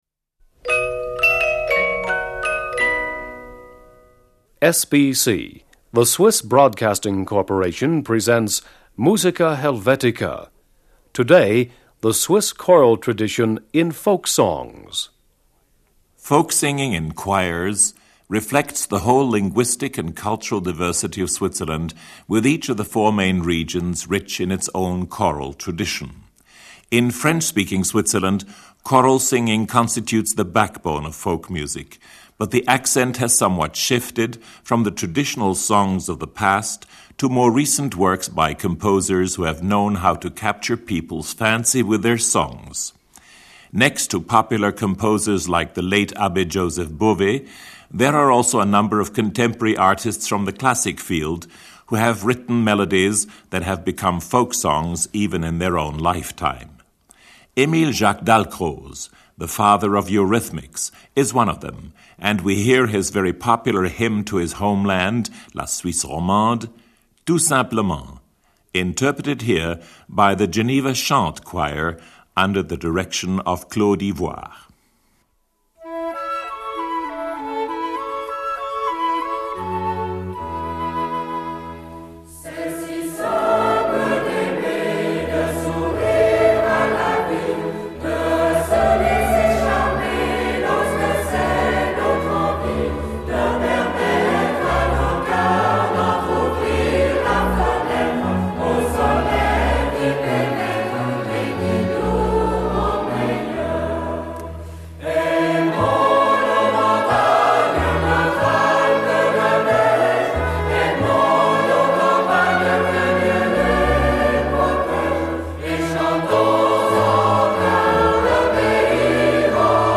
Each of the four main regions has its own choral tradition. This programme ranges from Romansch and roundelays to yodel and youth choirs.